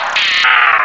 Cri_0452_DP.ogg